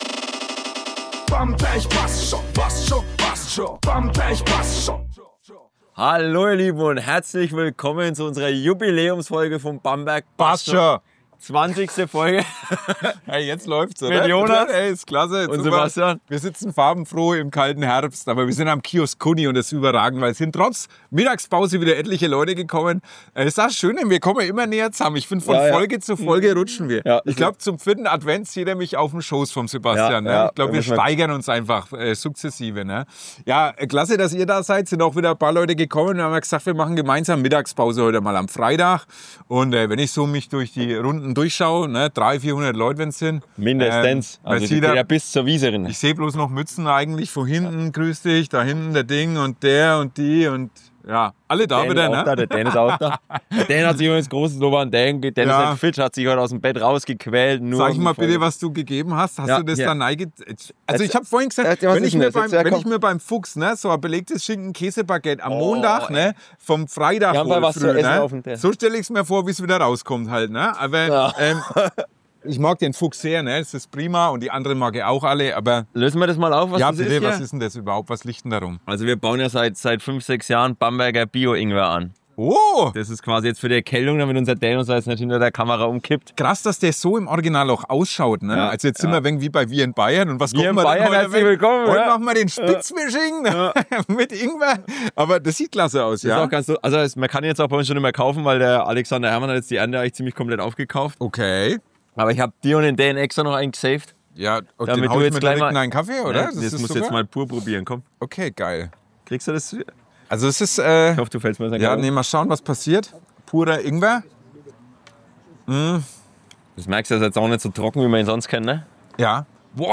In unserer 20. Folge setzen wir die Stadtteilreise fort und machen diesmal Mittagspause beim Kiosk Kunni. Freut euch auf eine picke packe volle Folge mit wichtigen Tipps für die kommenden Bockbieranstiche, Infos zum Stadtteil Wunderburg und einem Überraschungsgast, der uns etwas zur Galerie Kunni Hall erzählt.